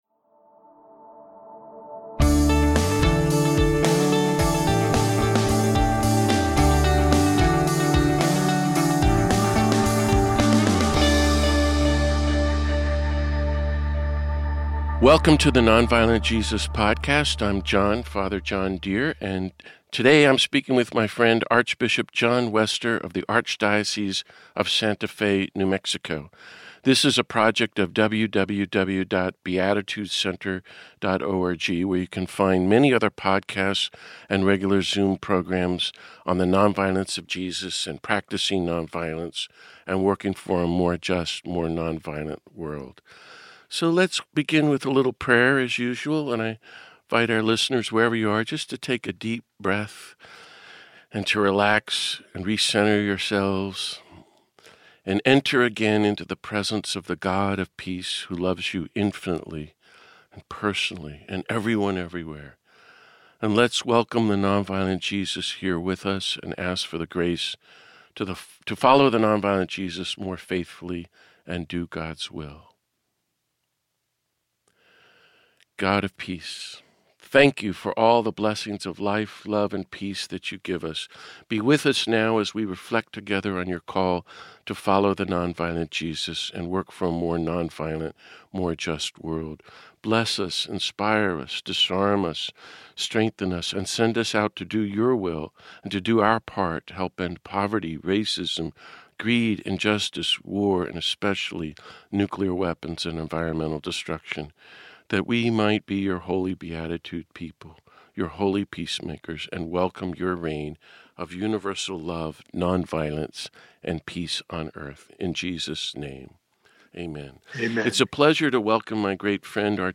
This week I speak with Archbishop John Wester of New Mexico about his pilgrimage of peace last month to Hiroshima and Nagasaki, Japan, for the 80th anniversary of the US atomic bombing, and his ground-breaking work of reconciliation with the bishops and church in Japan.